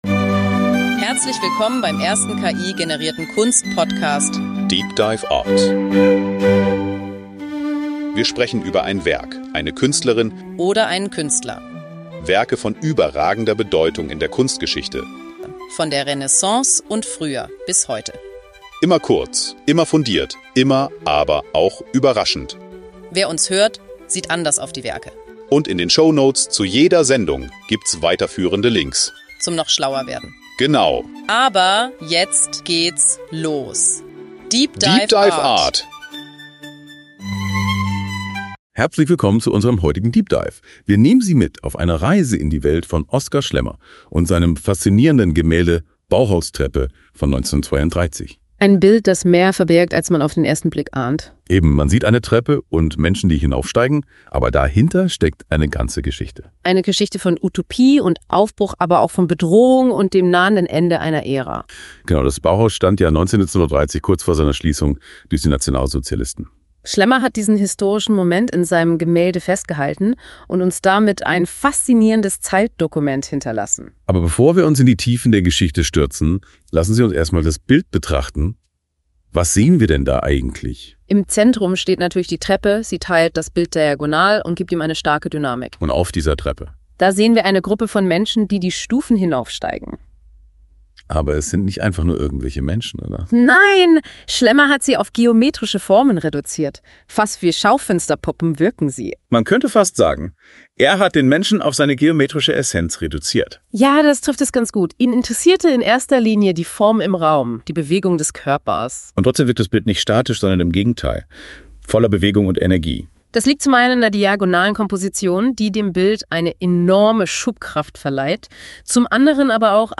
DEEP DIVE ART ist der erste voll-ki-generierte Kunst-Podcast.